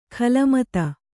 ♪ khala mata